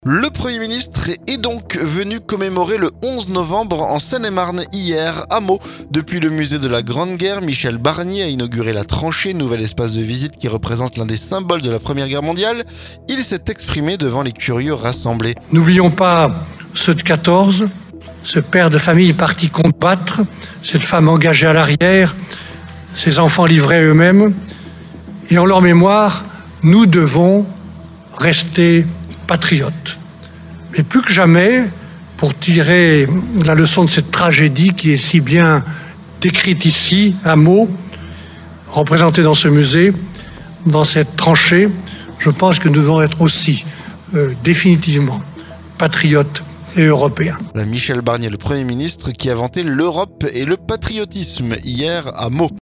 Il a inauguré la tranchée, nouvel espace de visite qui représente l'un des symboles de la première guerre mondiale. Michel Barnier s'est exprimé devant les curieux rassemblés.